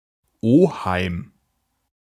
Ääntäminen
IPA: [ˈoːhaɪ̯m]